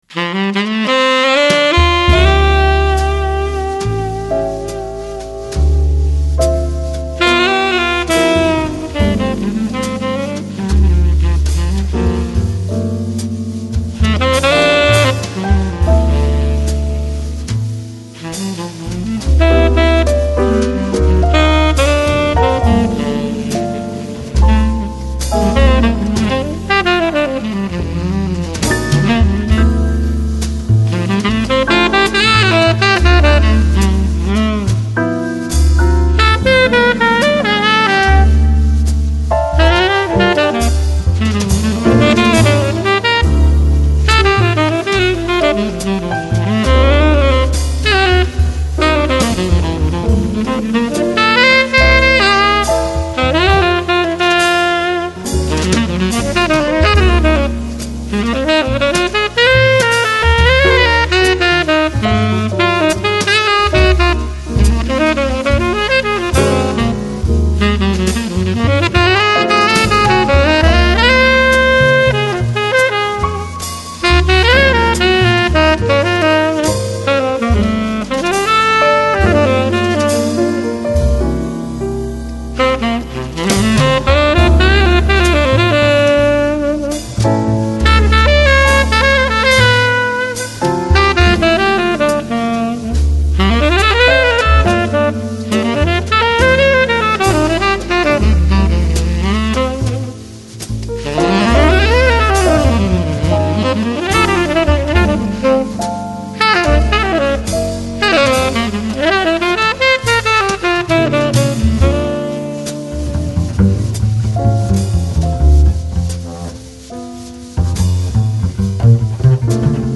Lounge, Downtempo, Bossa Nova, Nu Jazz